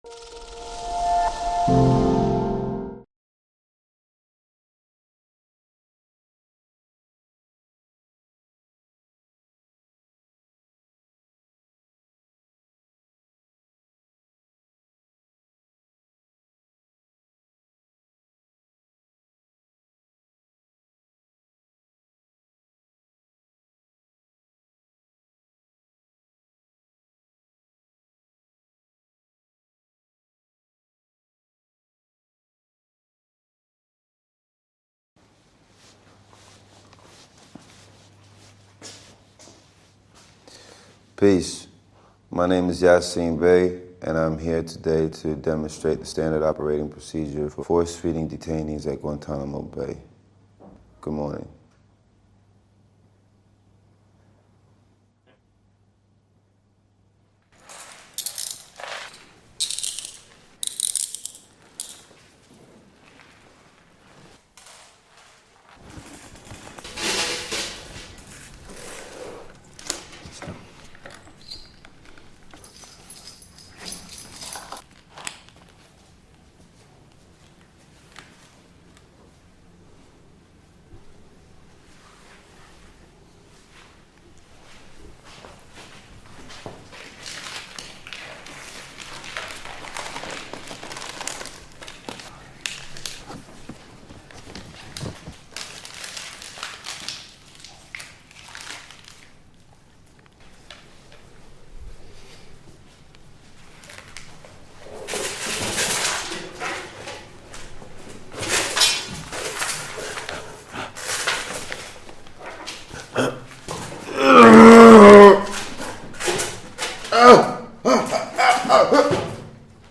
Yasiin Bey (aka Mos Def) force fed under standard Guantánamo Bay procedure
In this four-minute film made by Human Rights organisation Reprieve and Bafta award-winning director Asif Kapadia, US actor and rapper Yasiin Bey (formerly known as Mos Def), experiences the procedure.